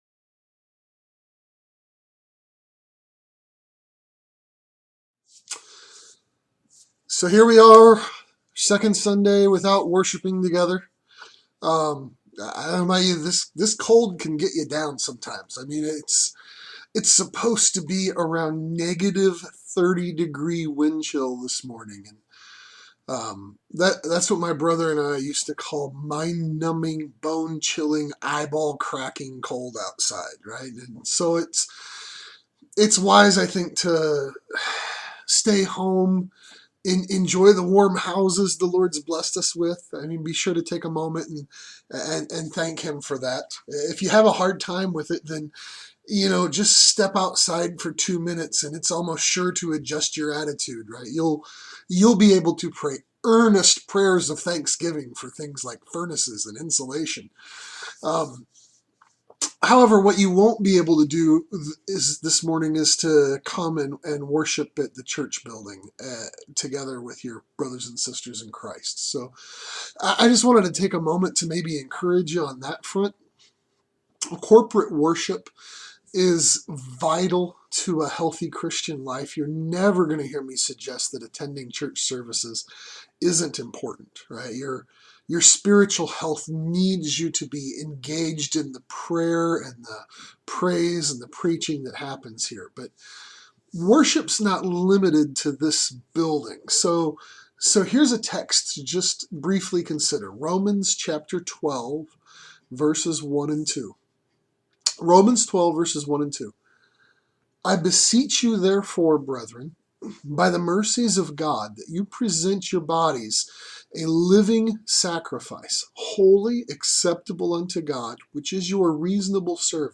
Whole-Life Worship | SermonAudio Broadcaster is Live View the Live Stream Share this sermon Disabled by adblocker Copy URL Copied!